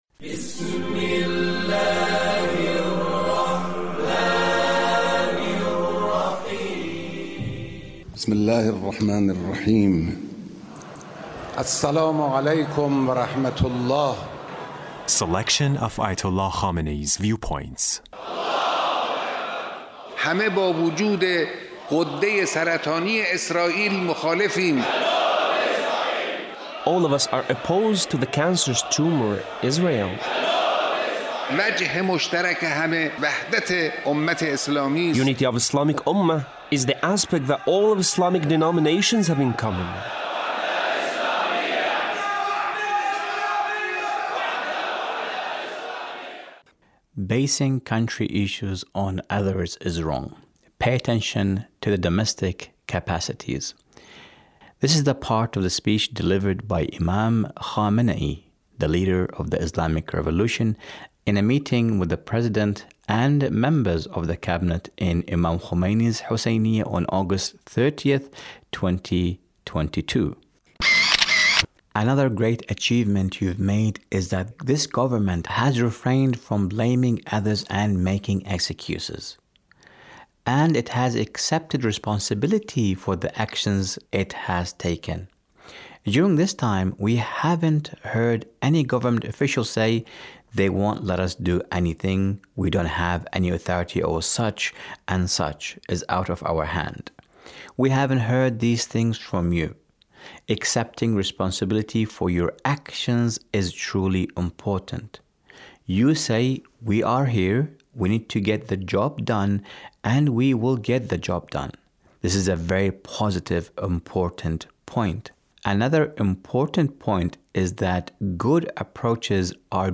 Leader's Speech on a Gathering with Friday Prayer Leaders